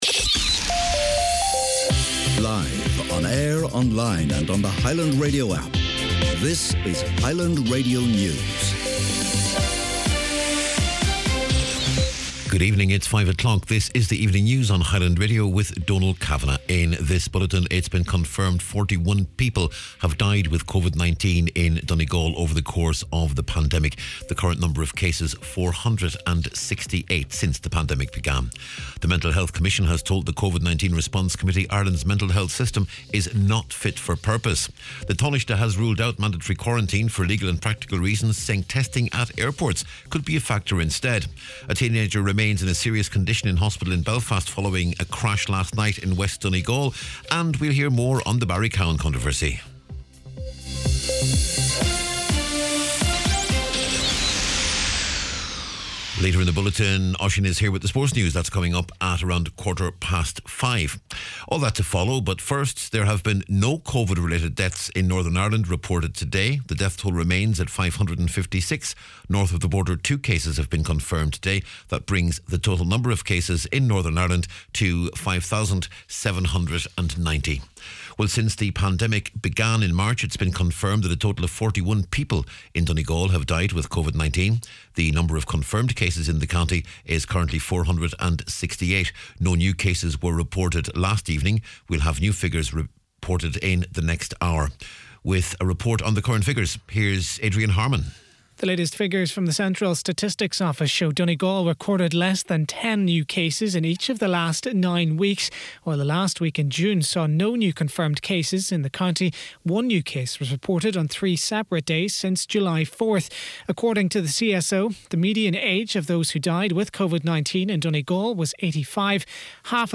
Evening News, Sport and Obituaries on Tuesday July 14th